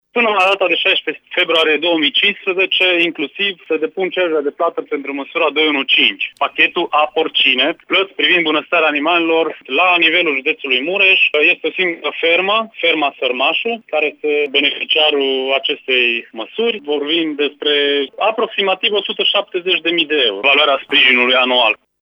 Este vorba de ferma de la Sărmașu, care va beneficia de suma de 170.000 de euro, a declarat directorul APIA Mureș, Ovidiu Săvâșcă.